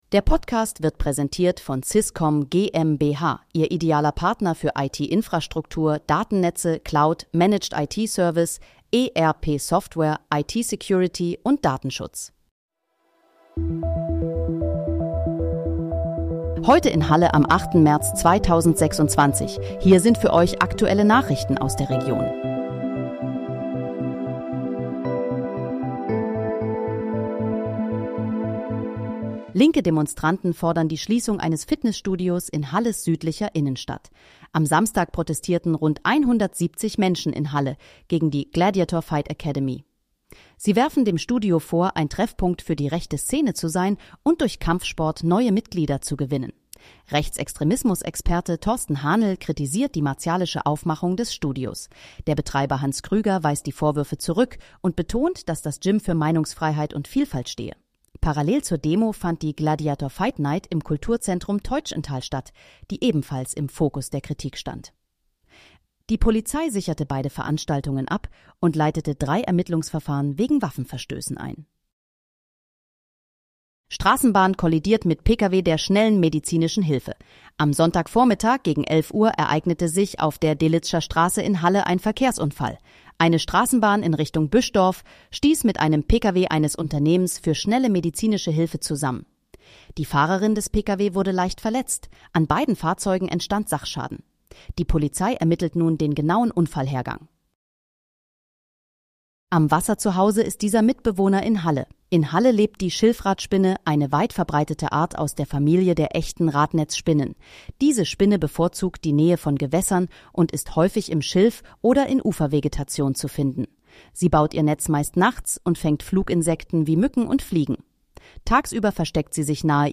Heute in, Halle: Aktuelle Nachrichten vom 08.03.2026, erstellt mit KI-Unterstützung